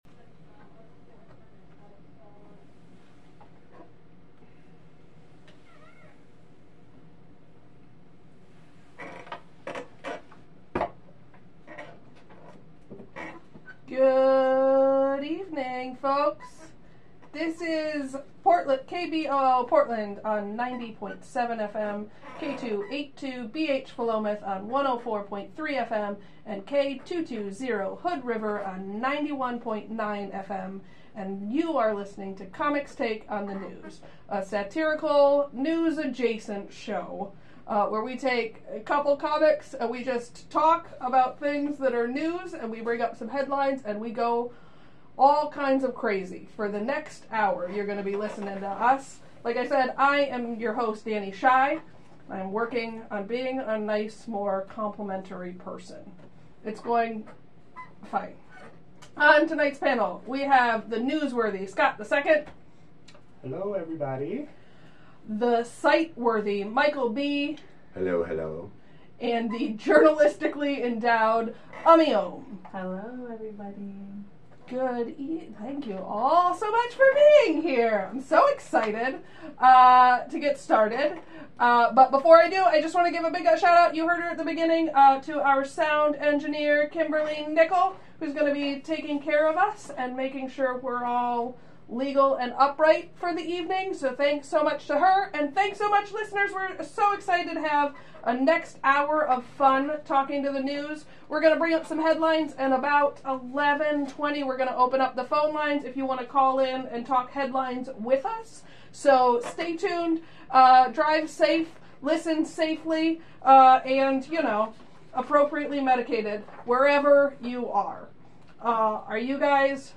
Comics Take on the News is a monthly radio show airing live on the 4th Monday of the month from 10pm - midnight.